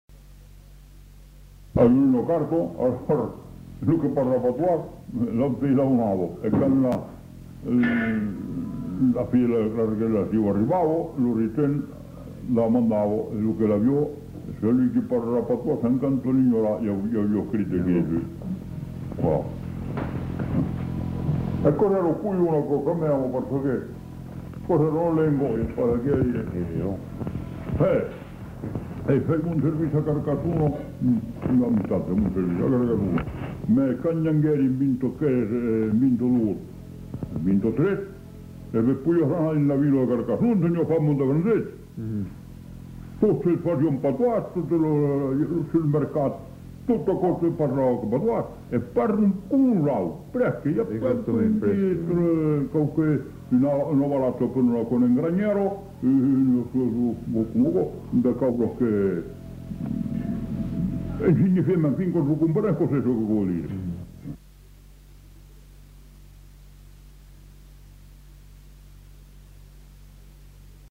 Aire culturelle : Haut-Agenais
Genre : témoignage thématique